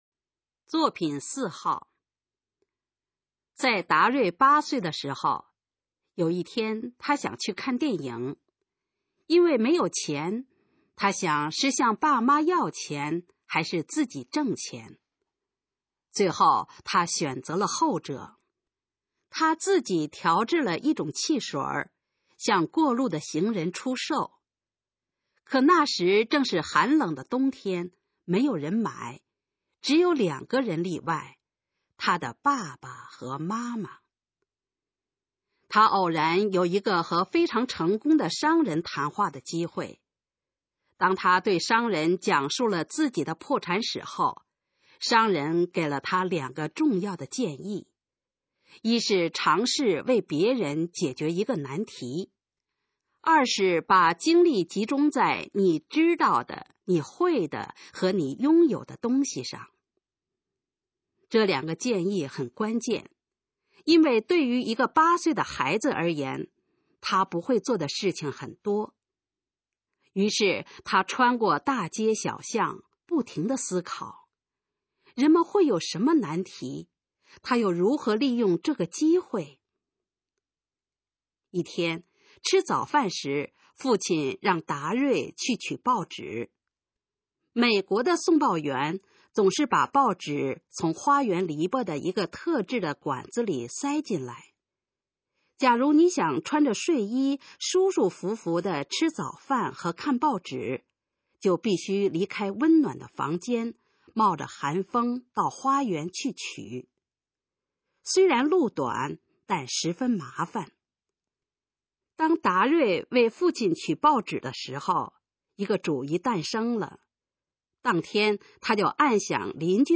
《达瑞的故事》示范朗读_水平测试（等级考试）用60篇朗读作品范读